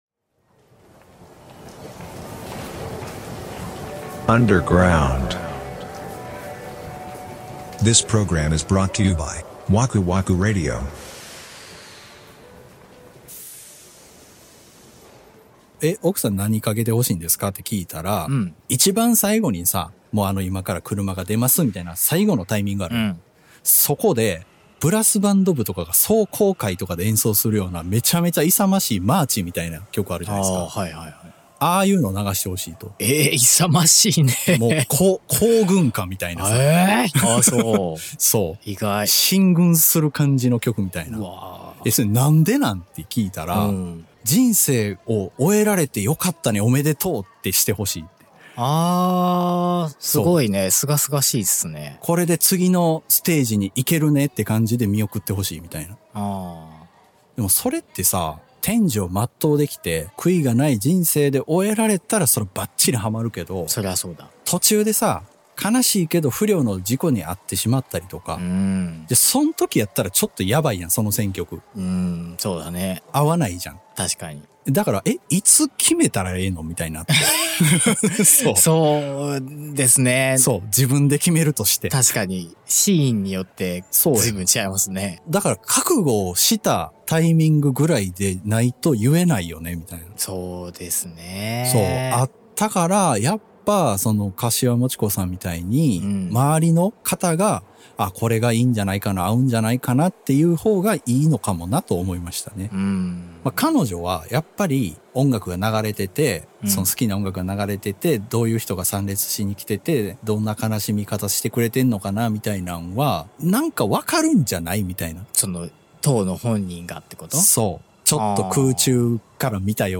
日常観察家事情報人生共感型 ジャスト10分バラエティ。 シュッとしたおっちゃんになりきれない、 宙ぶらり世代の２人が、 関西からお送りしています。